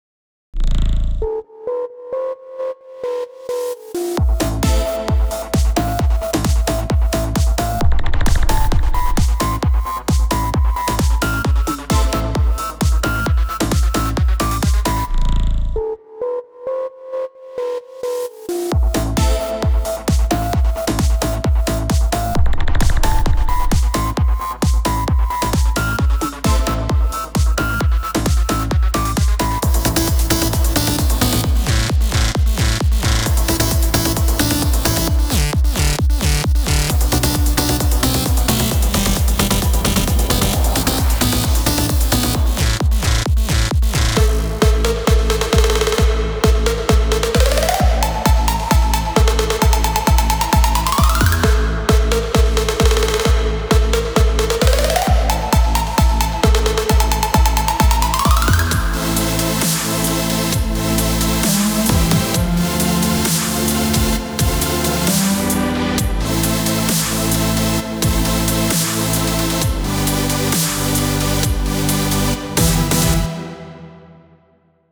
אחלה ביט - שווה האזנה
כדאי לעבוד על מיקס נכון, אבל יותר חשוב מזה - **אוקרדים נכונים! ** ונשמע שיש לך מה ללמוד אז בהצלחה יאלוף